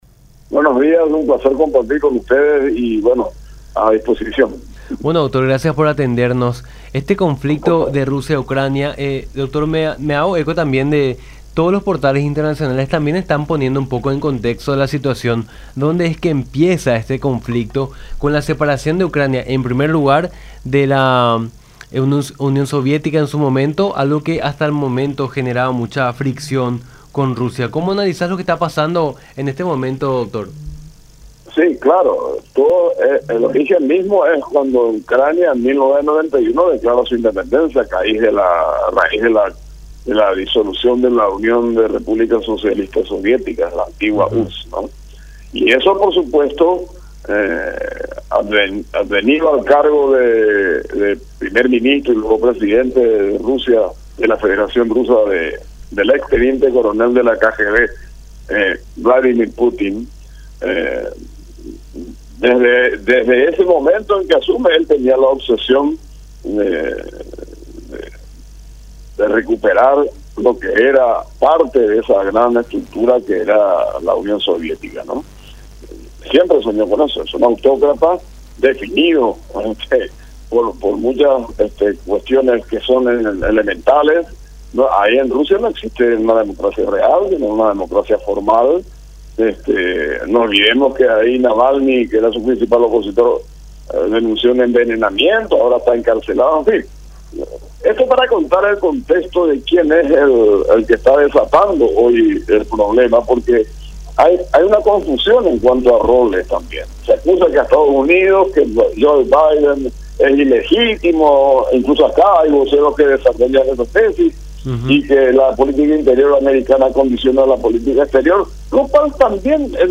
en conversación con Nuestra Mañana por La Unión